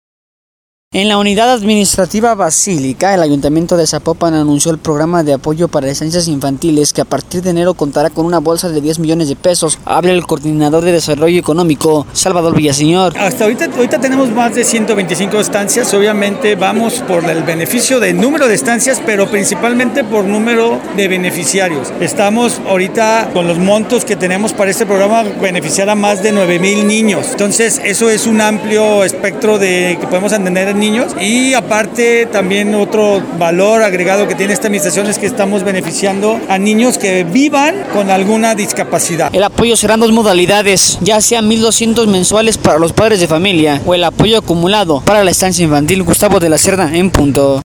En la Unidad Administrativa Basílica, el ayuntamiento de Zapopan, anunció el programa de apoyo para estancias infantiles, que a partir de enero contará con una bolsa de 10 millones de pesos. Habla el coordinador de Desarrollo Económico, Salvador Villaseñor: